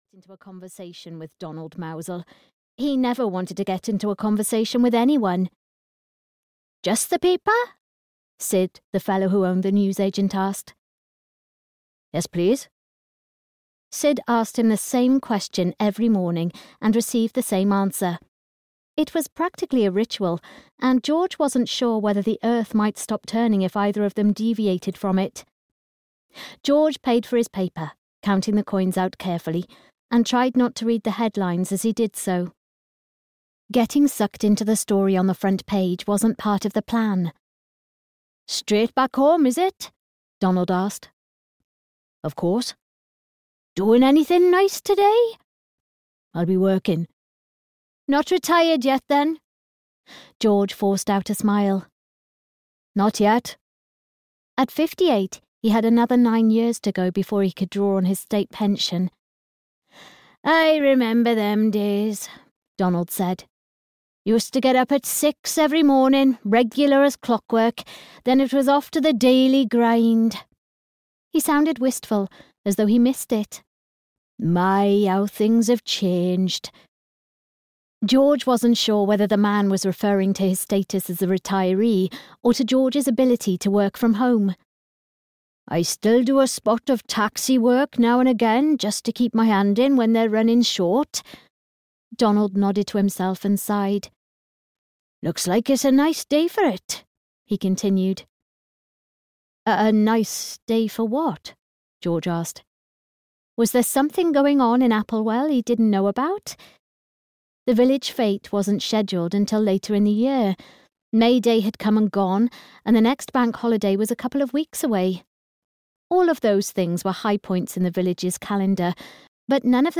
Waste Not, Want Not in Applewell (EN) audiokniha
Ukázka z knihy